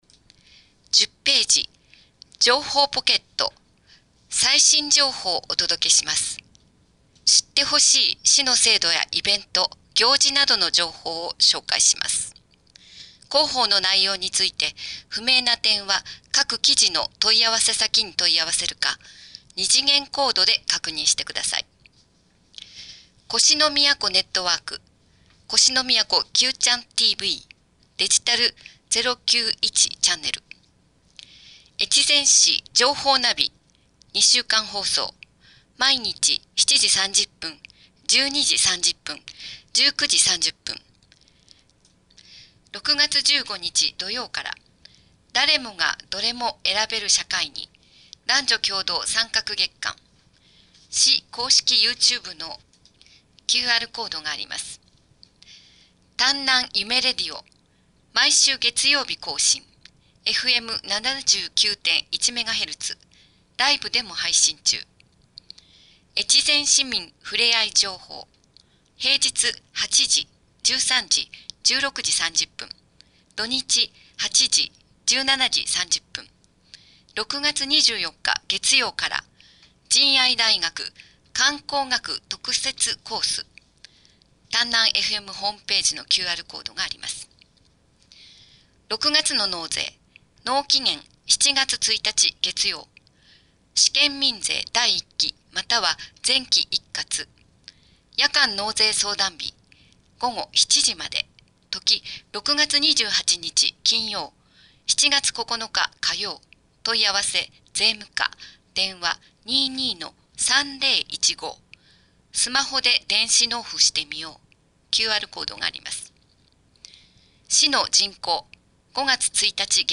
越前市広報６月号（音訳）